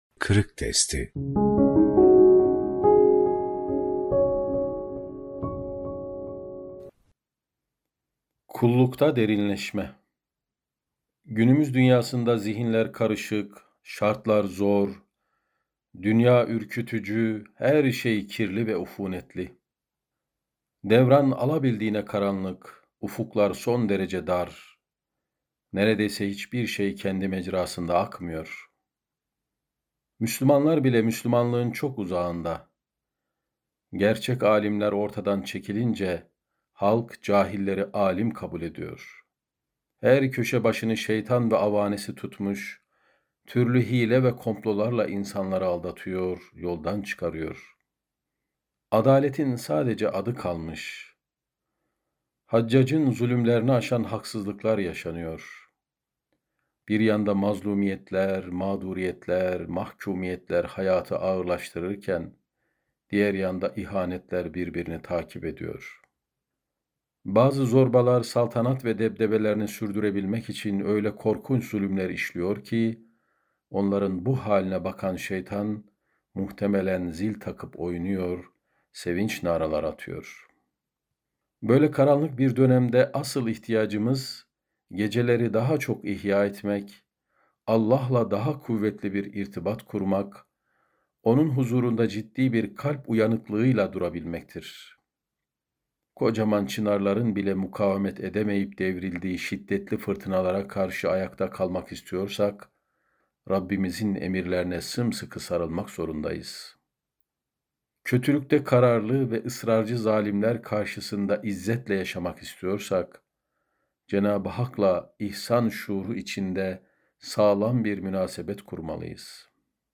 Kullukta Derinleşme - Fethullah Gülen Hocaefendi'nin Sohbetleri